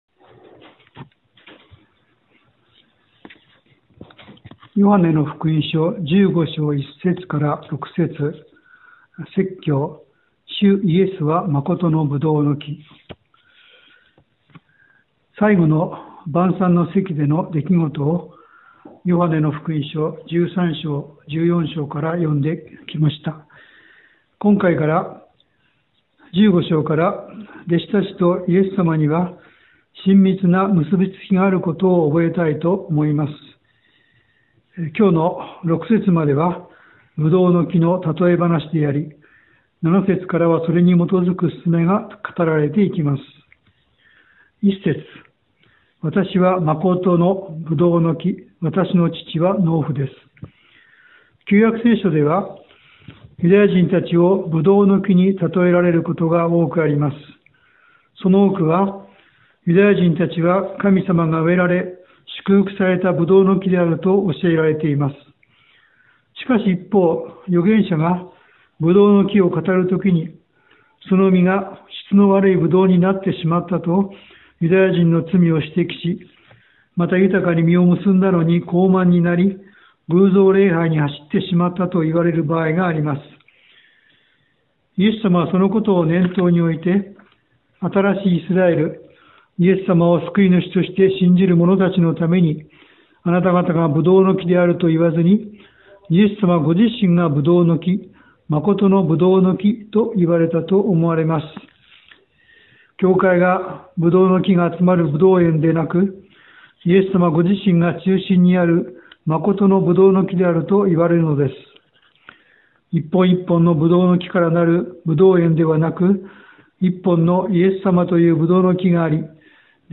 Sermon
当日の説教 事前録音分 説教要旨 イエス様が弟子たちに望んでおられることは、イエス様にとどまりつづけることです。